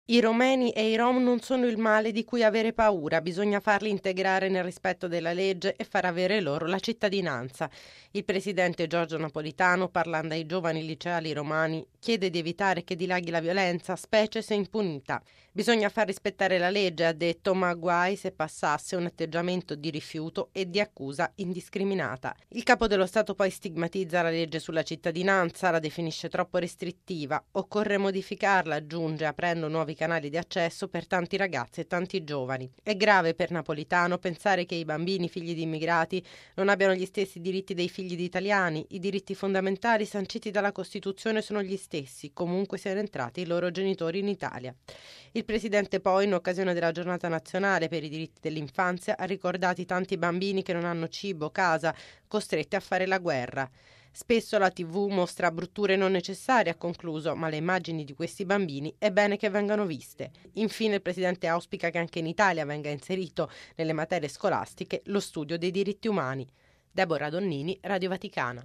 Occorre allargare la cittadinanza. Lo ha detto oggi il presidente della repubblica Napolitano che, al Quirinale, in occasione della 18.ma giornata mondiale per i diritti dell’infanzia e dell’adolescenza ha ricevuto alcuni liceali romani.
Servizio